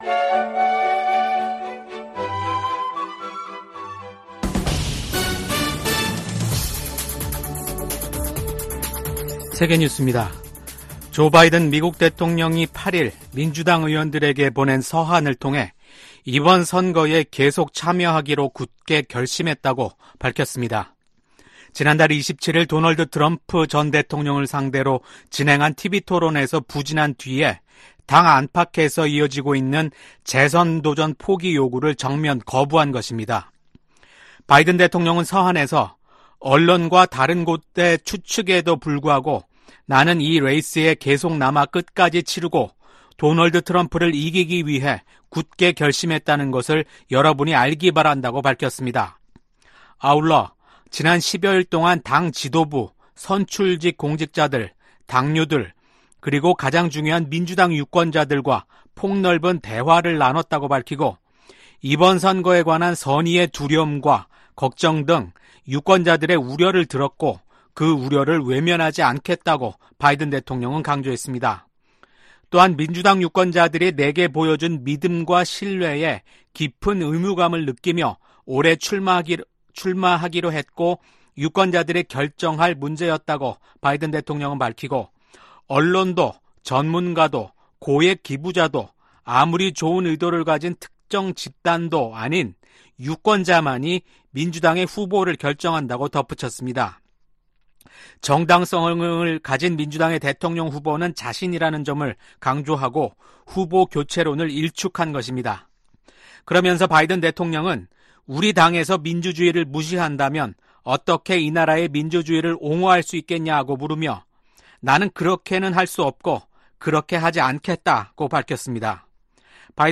VOA 한국어 아침 뉴스 프로그램 '워싱턴 뉴스 광장' 2024년 7월 9일 방송입니다. 북한의 핵과 미사일 프로그램이 주변국과 세계안보에 대한 도전이라고 나토 사무총장이 지적했습니다. 윤석열 한국 대통령은 북-러 군사협력이 한반도와 국제사회에 중대한 위협이라며 한-러 관계는 전적으로 러시아에 달려 있다고 말했습니다. 미국 고위관리는 중국의 러시아 지원이 전쟁을 부추기고 있으며, 워싱턴 나토 정상회의에서 이 문제가 중요하게 논의될 것이라고 밝혔습니다.